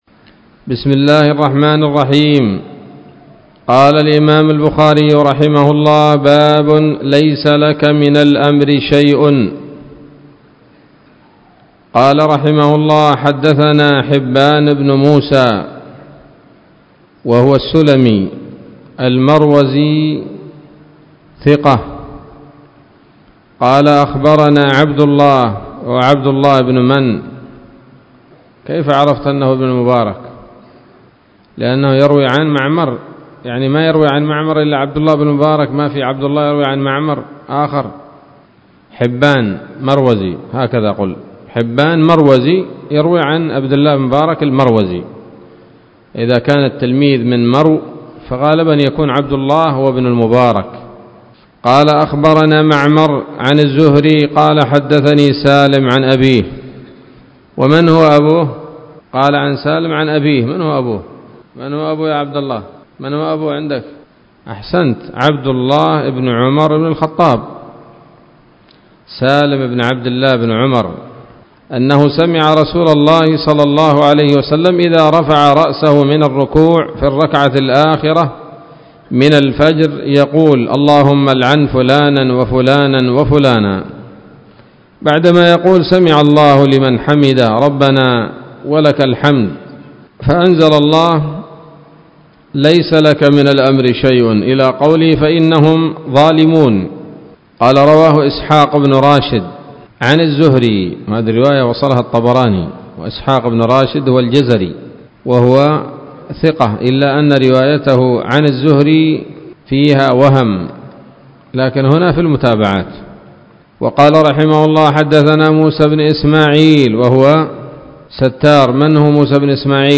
الدرس الثالث والخمسون من كتاب التفسير من صحيح الإمام البخاري